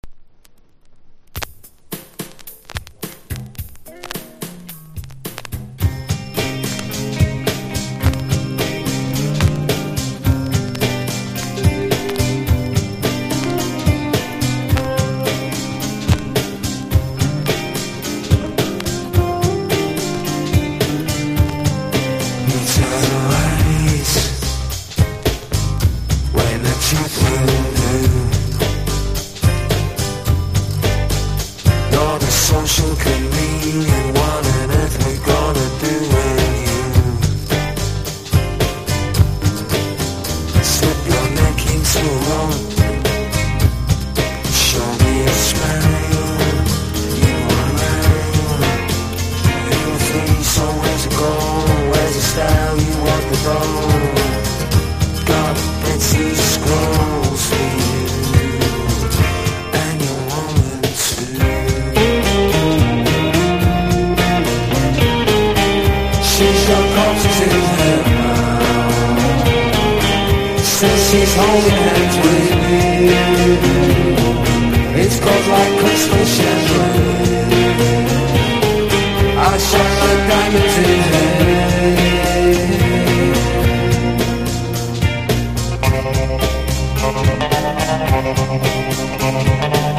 1. 90'S ROCK >